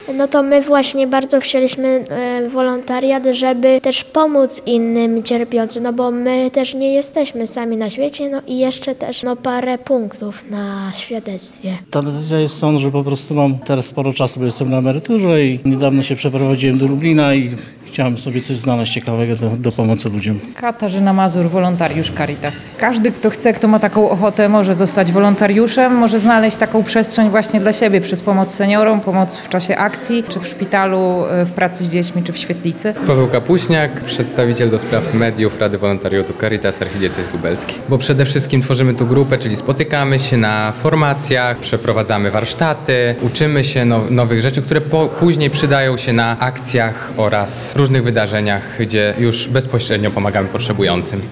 Środowe (08.10) spotkanie odbyło się w Domu Nadziei w Lublinie.